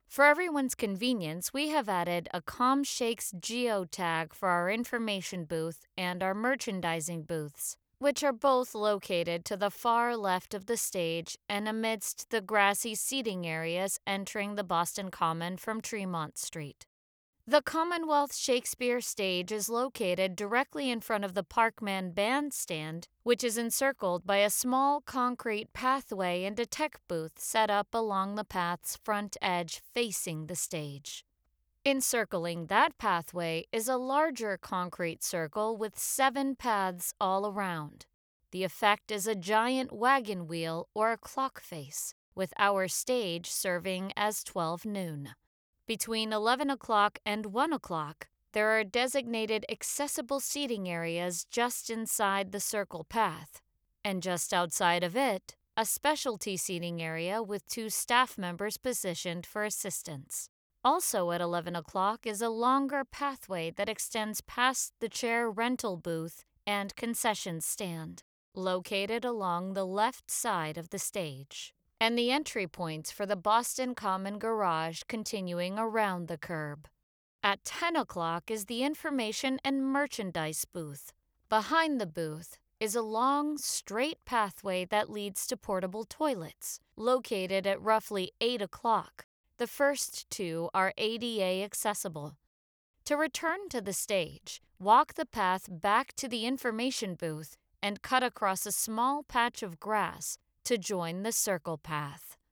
Audio Description of Boston Common and Wayfinding Description: